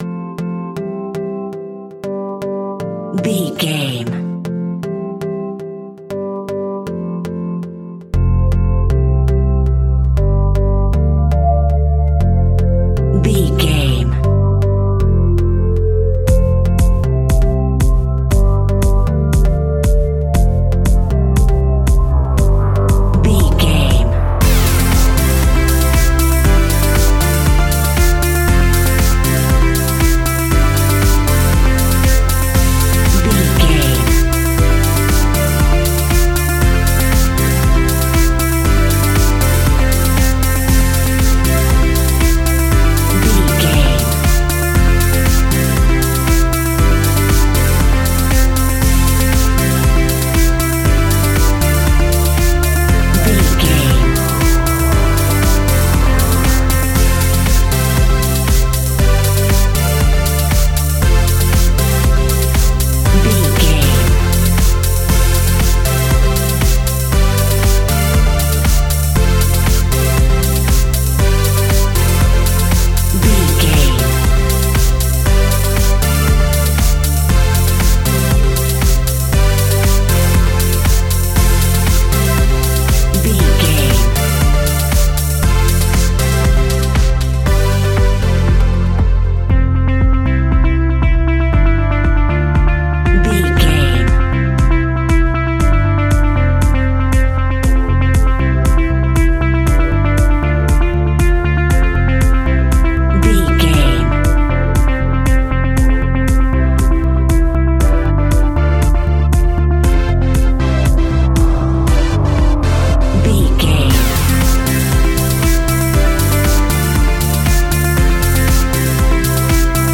Aeolian/Minor
uplifting
futuristic
energetic
repetitive
bouncy
synthesiser
drum machine
electronic music
synth bass
synth lead
synth pad
robotic